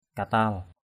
/ka-ta:l/ (d.) sét = foudre. lightning. grum katal g~’ ktL sấm sét = orage. thunderstorm. ajaong katal a_j” ktL lưỡi sét = éclair. thunderbolt. batuw katal bt~| ktL đá sét...
katal.mp3